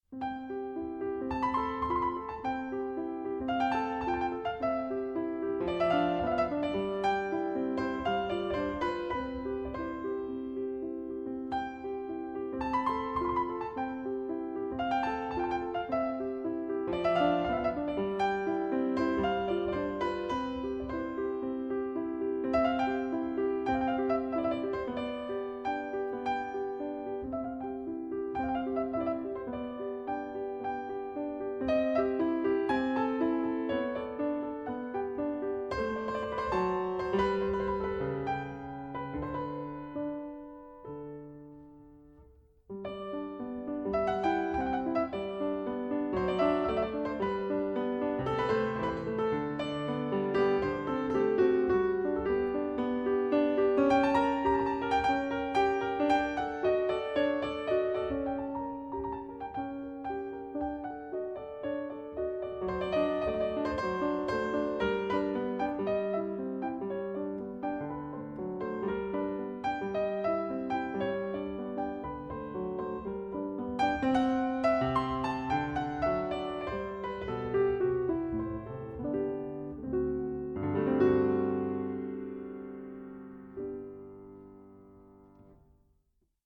This is no ordinary recorded recital.